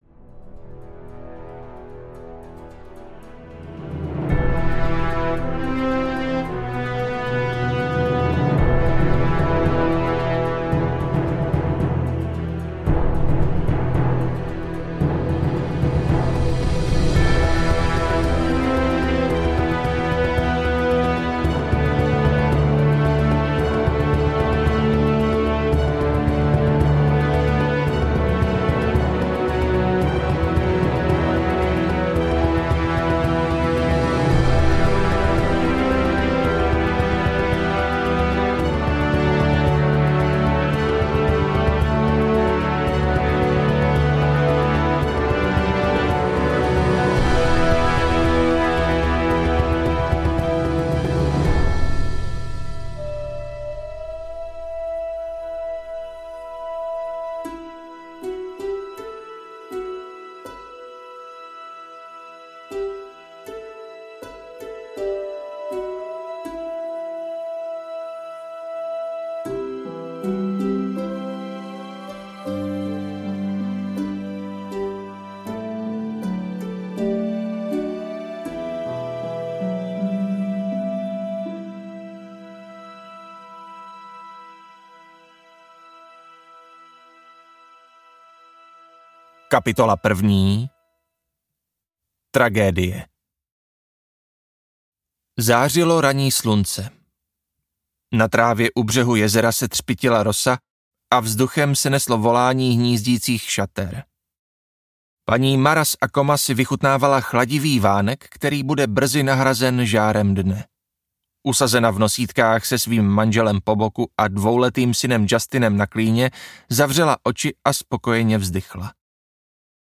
Správce impéria: Vrah audiokniha
Ukázka z knihy